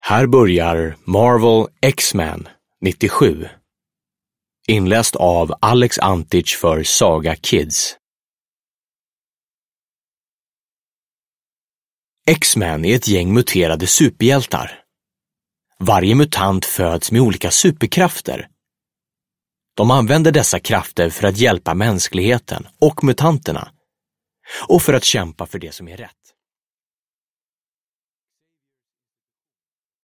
X-Men '97 – Ljudbok